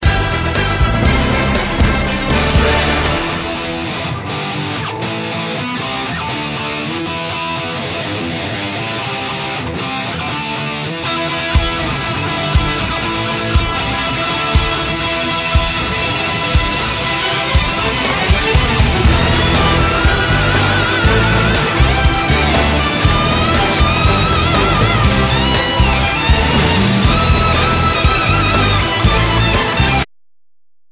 key: E minor